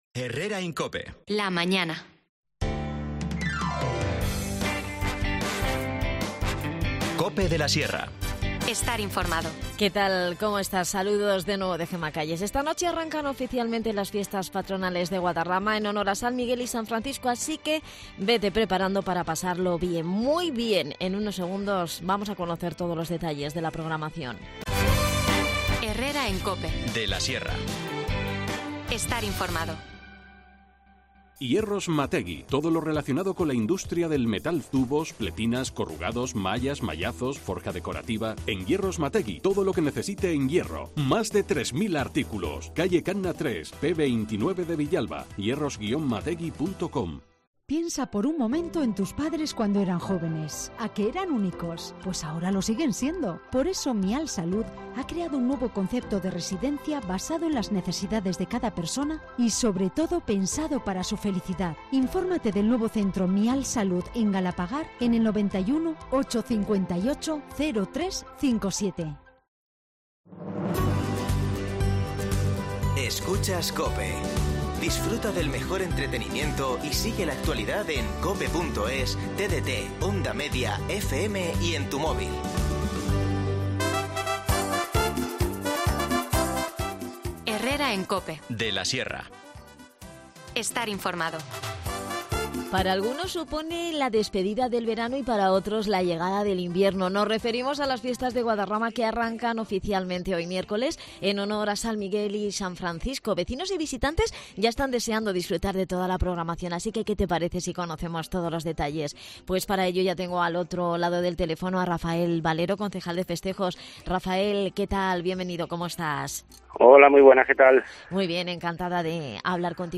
Rafael Valero, concejal de Festejos, nos detalla toda la programación.